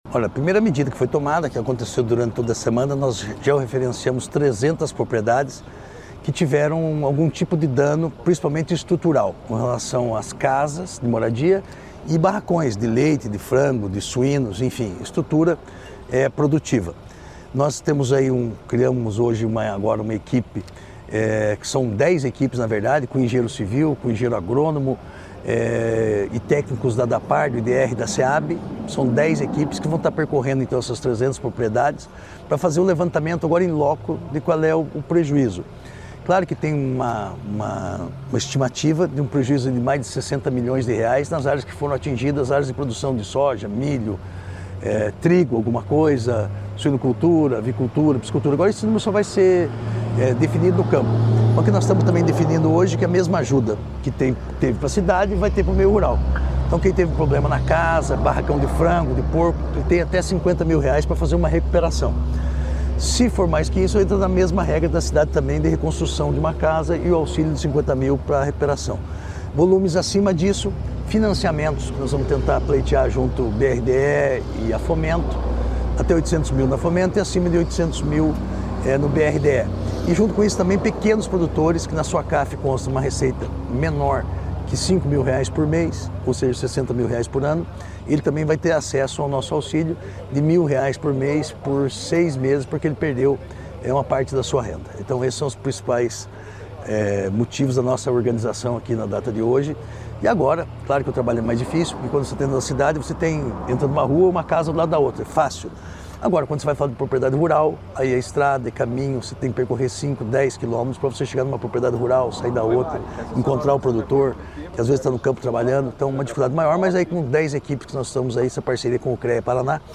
Sonora do secretário da Agricultura e do Abastecimento, Márcio Nunes, sobre o auxílio para o setor agropecuário de Rio Bonito do Iguaçu